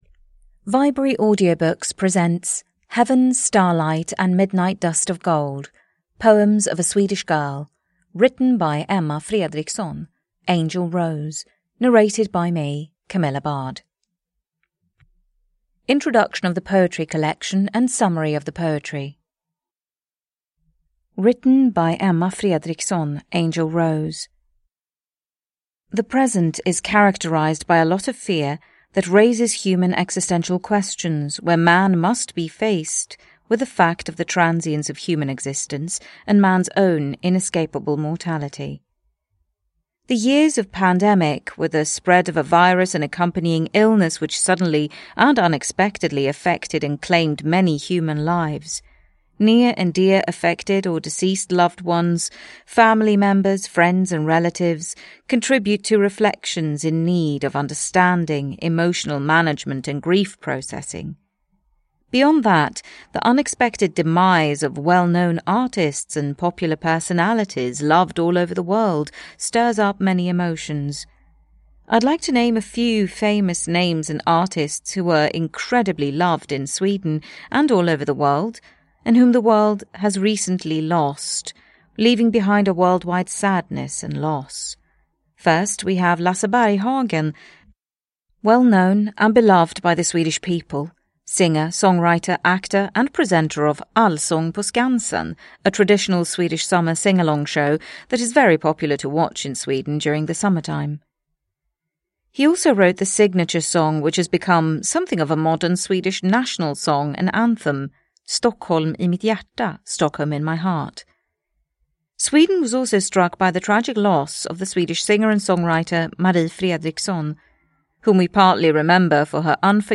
Lyrik
Ljudbok